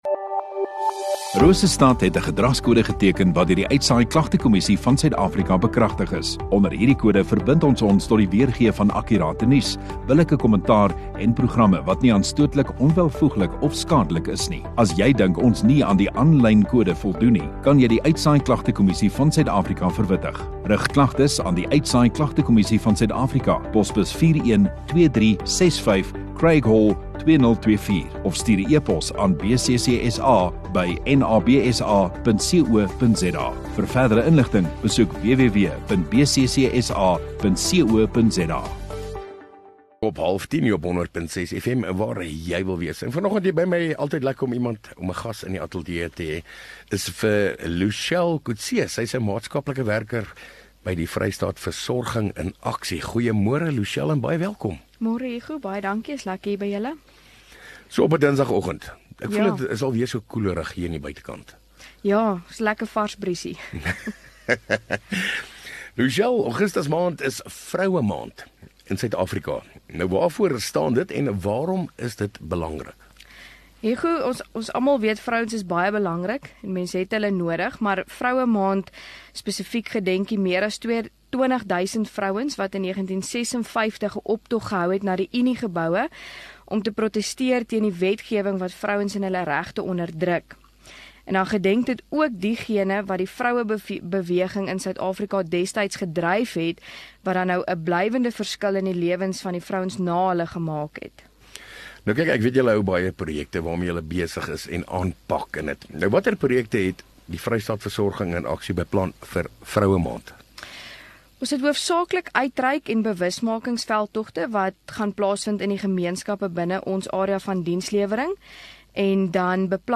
View Promo Continue Radio Rosestad Install Gemeenskap Onderhoude 23 Jul VVA Vrystaat nasorgsentrum